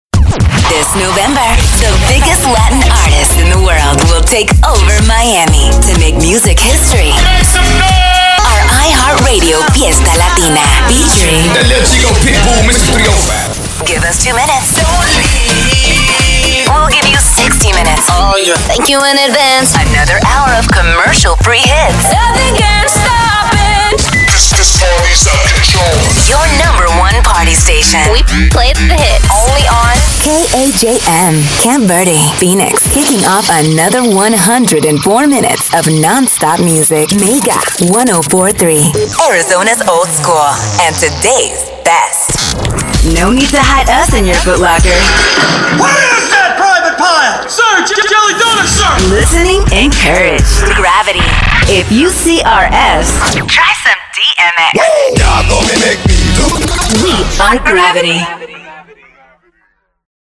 Female Voice Over, Dan Wachs Talent Agency.
Sassy, Sincere, Confident
Radio Imaging